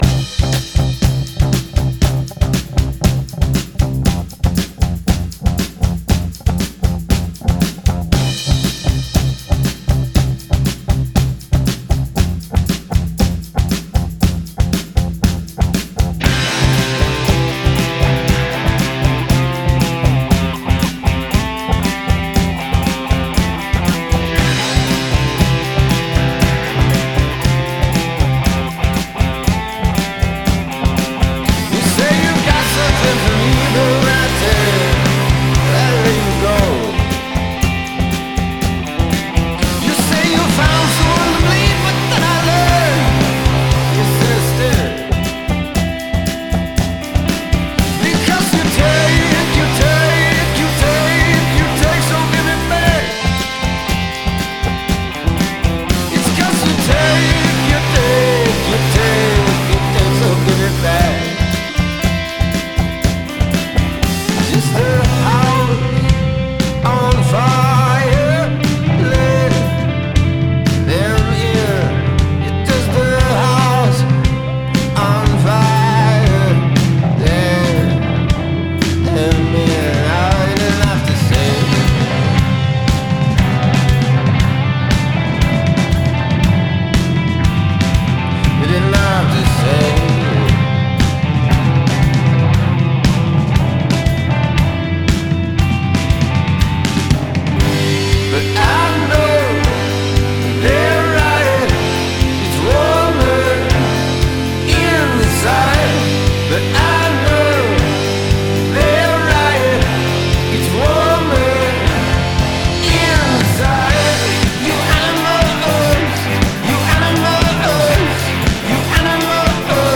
le rock nerveux